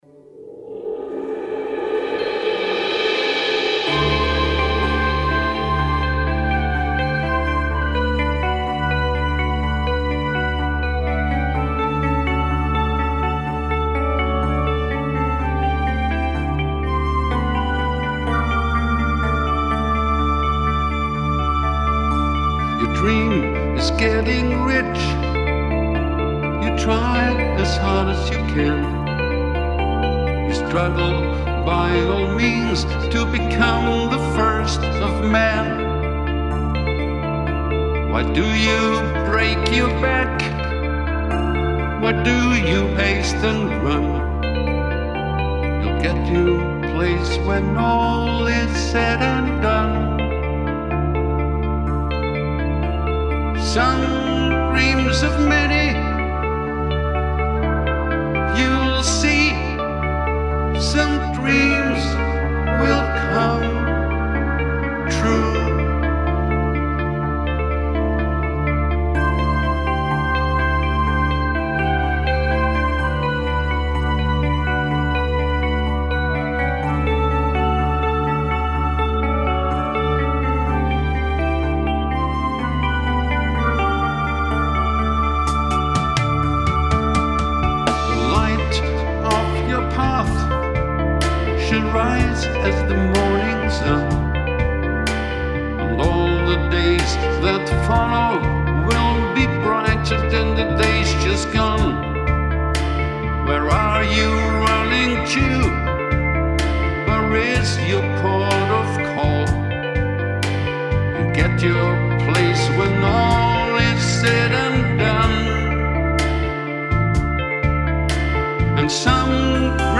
Keyboards, Gitarre, Drum Computer